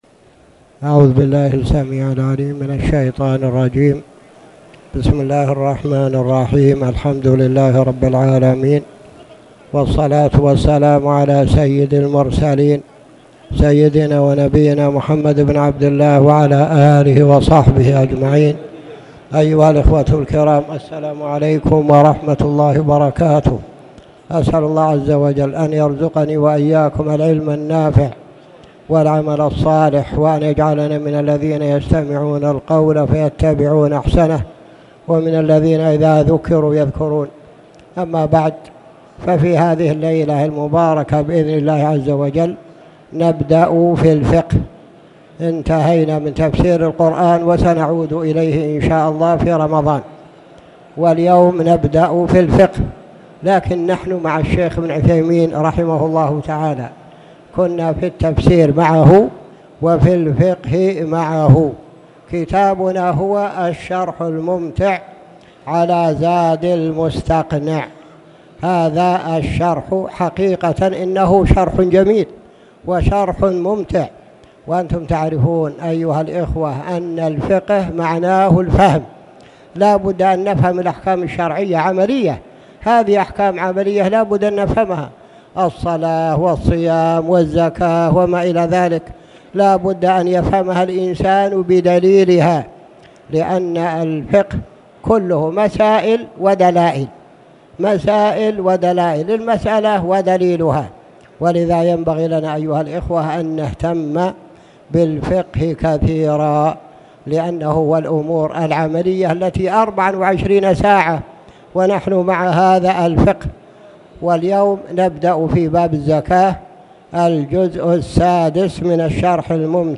تاريخ النشر ٦ جمادى الآخرة ١٤٣٨ هـ المكان: المسجد الحرام الشيخ